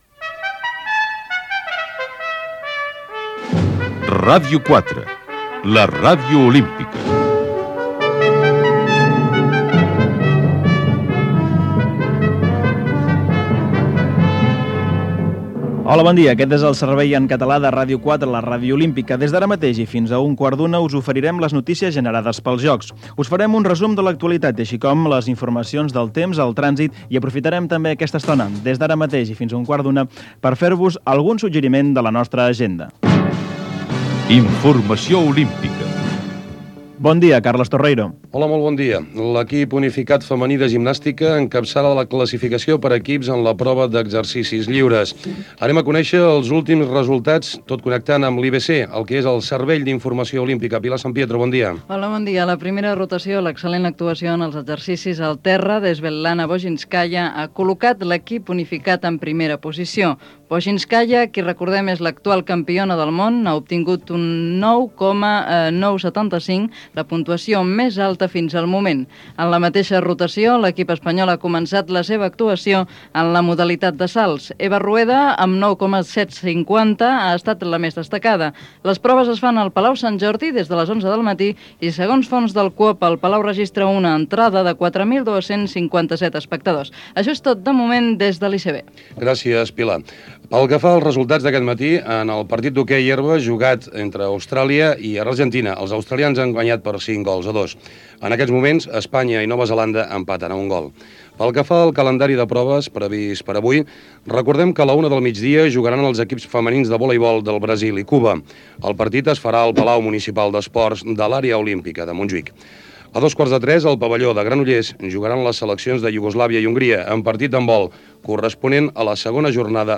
bb489e85b0a2811aa5d5eaa0a9a9982db7811b6d.mp3 Títol Ràdio 4 la Ràdio Olímpica Emissora Ràdio 4 la Ràdio Olímpica Cadena RNE Titularitat Pública estatal Descripció Maqueta interna amb informacions esportives fictícies abans que funcionés Ràdio 4 la Ràdio Olímpica.
Tema musical i comiat